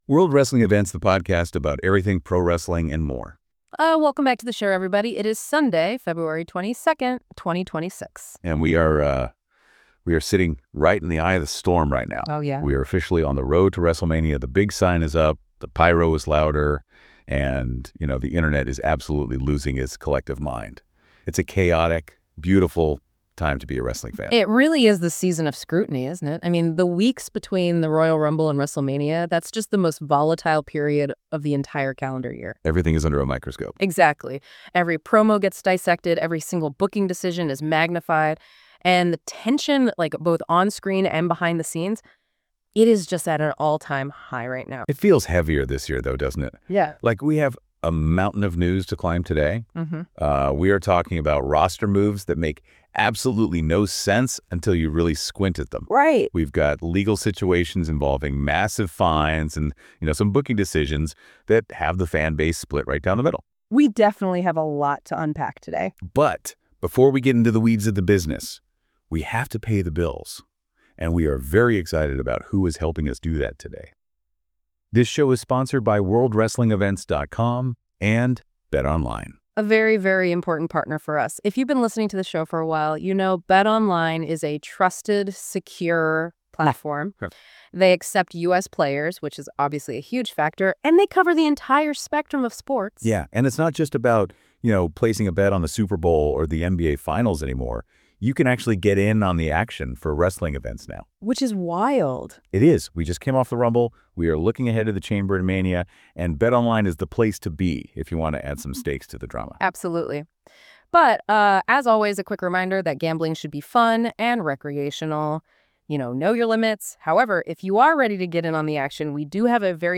With the road to WrestleMania heating up, they explore how every promo, match announcement, and backstage decision gets magnified under the spotlight, setting the stage for a wide-ranging conversation that blends fan passion with smart wrestling analysis.